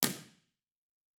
Loudspeaker: Isobel Baritone Half-dodec
Microphone: Sennheiser Ambeo
Source: 14 sec log sweep
Playback RIR:
Test Position 2 – 10 m – XYStereo
RIR_TP2_Isobel_Ambio_10m_B4_XYSTEREO.wav